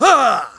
Riheet-Vox_Attack4.wav